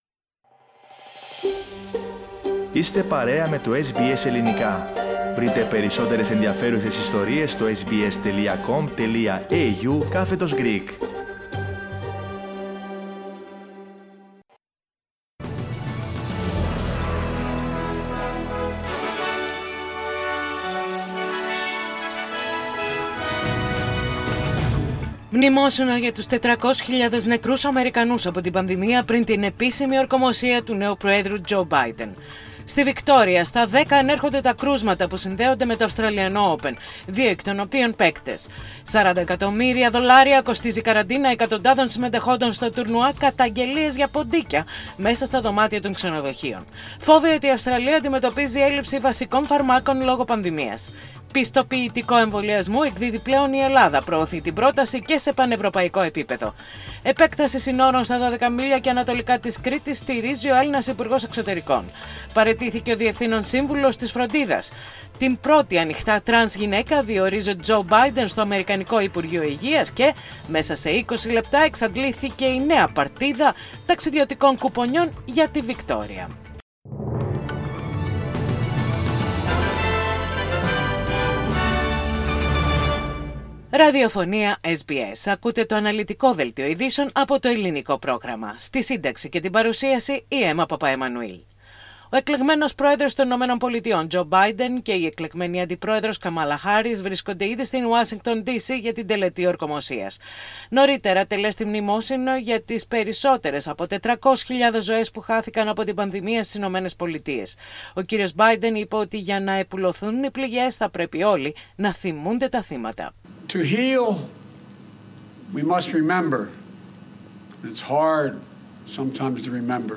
Δελτίο Ειδήσεων - Τετάρτη 20.1.21
Οι κυριότερες ειδήσεις της ημέρας από το Ελληνικό πρόγραμμα της ραδιοφωνίας SBS.